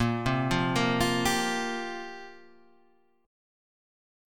Fsus2sus4/Bb chord